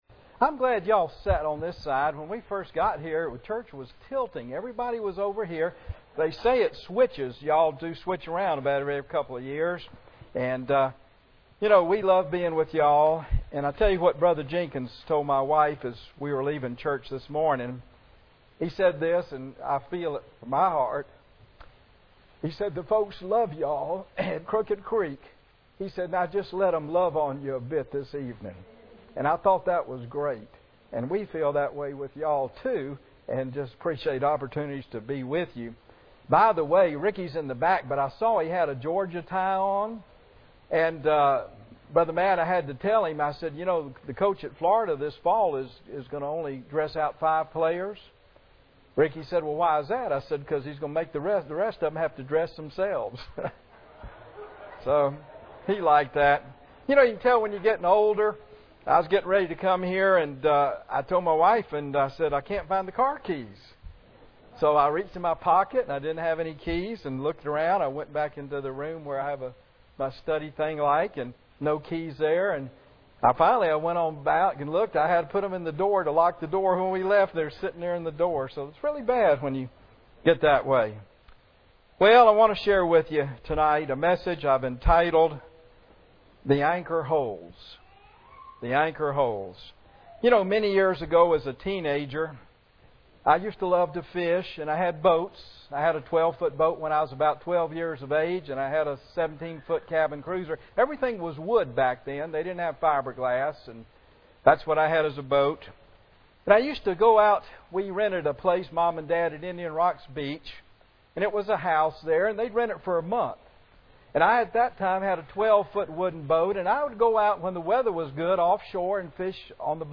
2 Corinthians 1:3-4 Service Type: Sunday Evening Bible Text